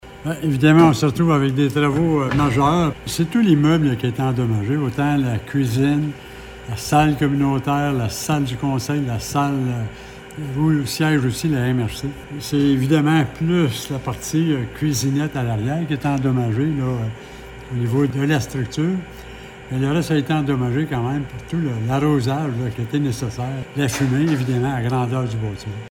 Bernard Duffy, qui a présidé la séance du mois d’août en raison de l’absence du maire et du maire suppléant, parle des dégâts au centre municipal :